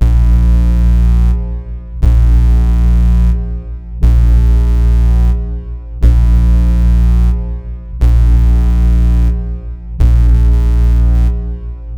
Alarms